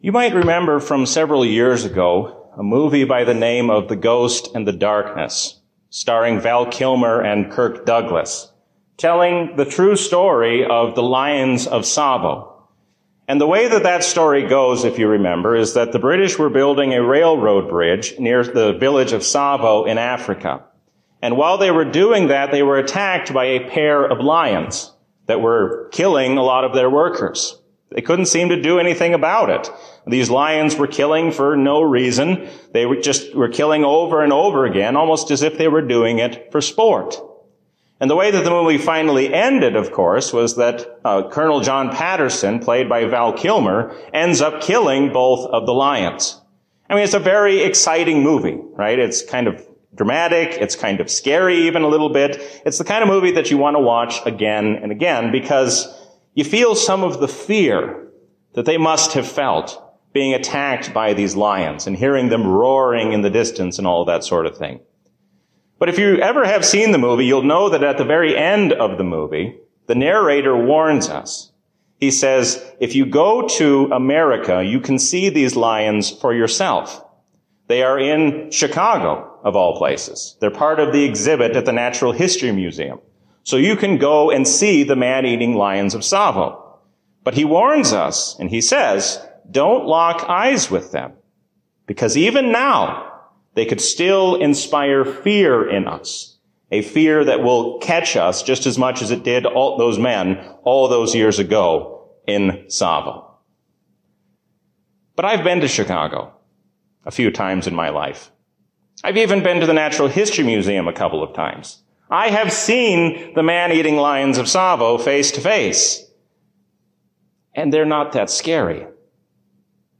A sermon from the season "Trinity 2022." Stand firm against the hostile world, because Jesus reigns as the King of Kings and Lord of Lords forever.